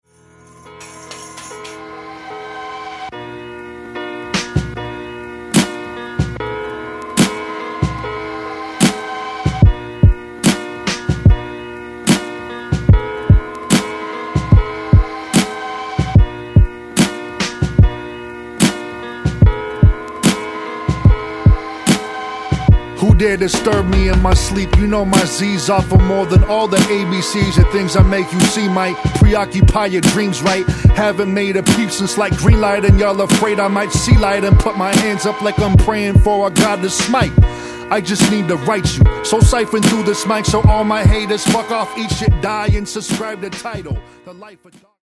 Genre : Hip-hop/Rap